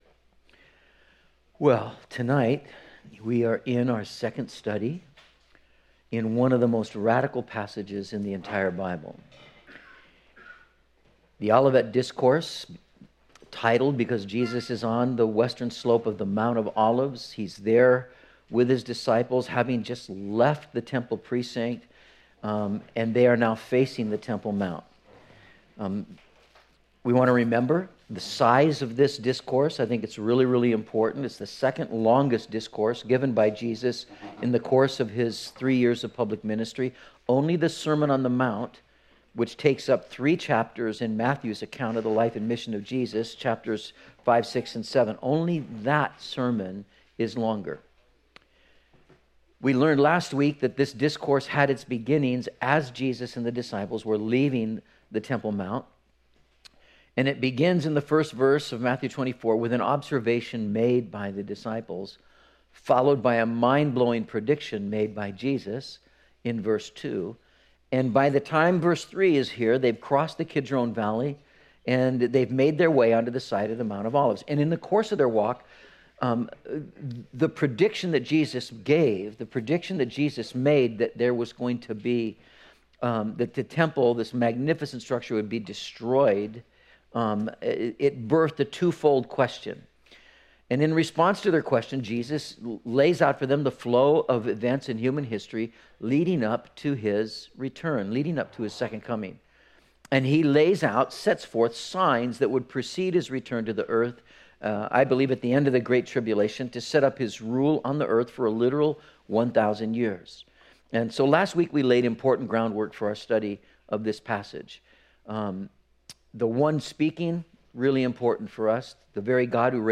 03/04/19 The Beginning of the End - Metro Calvary Sermons